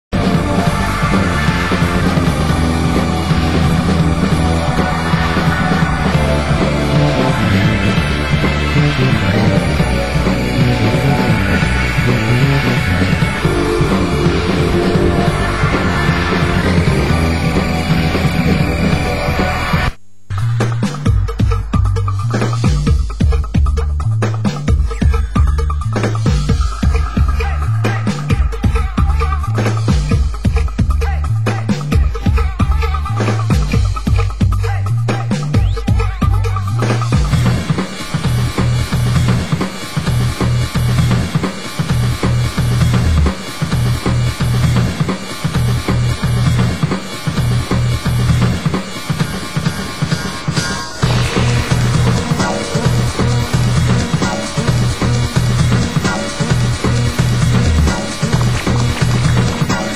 Genre: Future Jazz